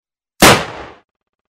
Звуки стартового пистолета
Звук выстрела из пистолета